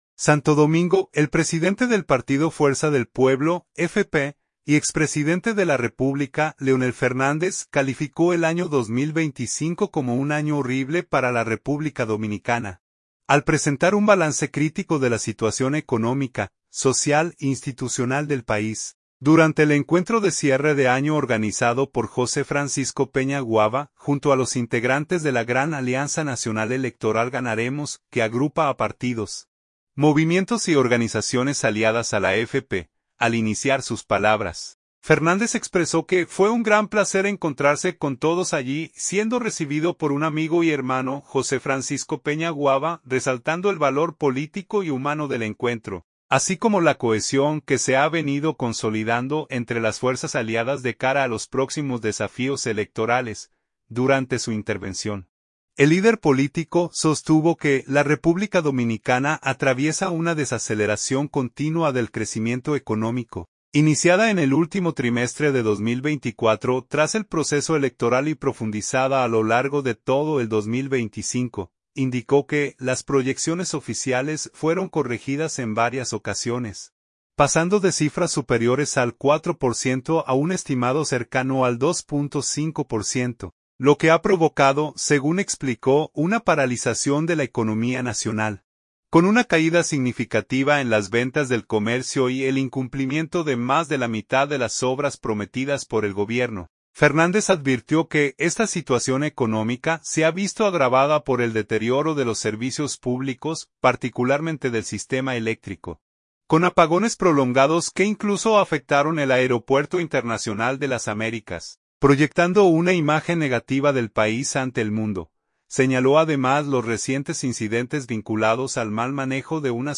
Santo Domingo.- El presidente del partido Fuerza del Pueblo (FP) y expresidente de la República, Leonel Fernández, calificó el año 2025 como “un año horrible para la República Dominicana”, al presentar un balance crítico de la situación económica, social e institucional del país, durante el encuentro de cierre de año organizado por José Francisco Peña Guaba, junto a los integrantes de la Gran Alianza Nacional Electoral (GANAREMOS), que agrupa a partidos, movimientos y organizaciones aliadas a la FP.